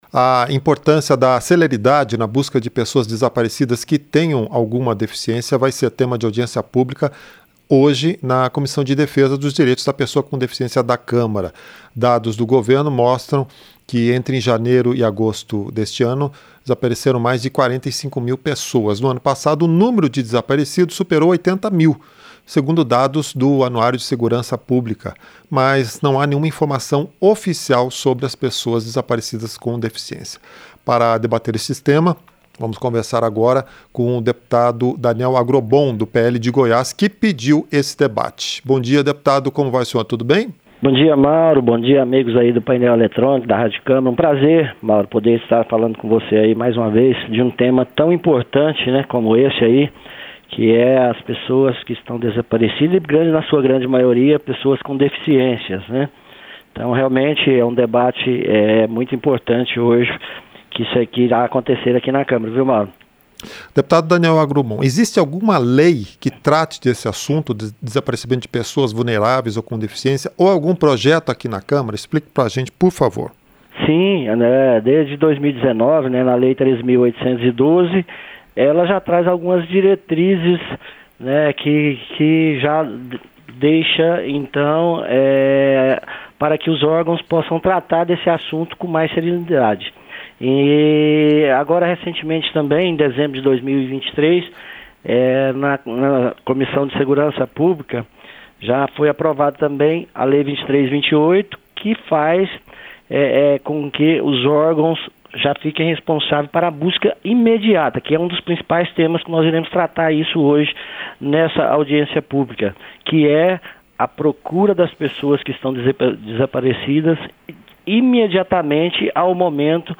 • Entrevista - Dep. Daniel Agrobom (PL-GO)
Programa ao vivo com reportagens, entrevistas sobre temas relacionados à Câmara dos Deputados, e o que vai ser destaque durante a semana.